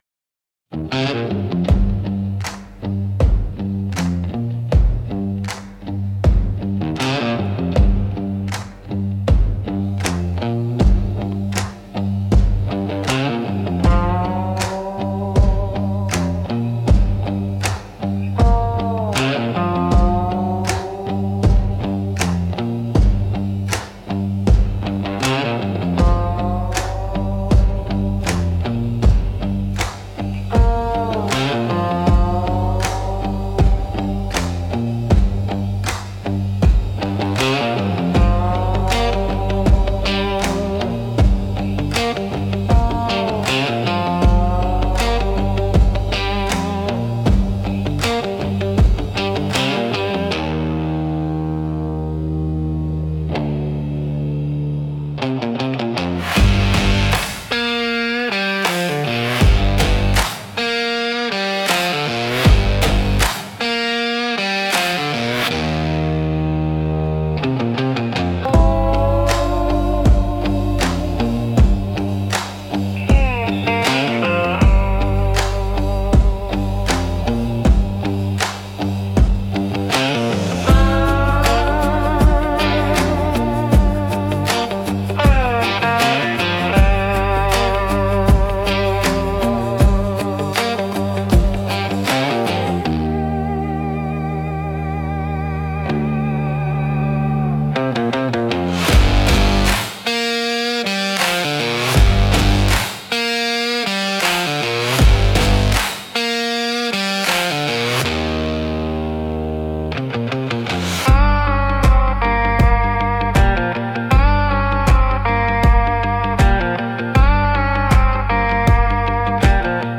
Gritty Country Stomp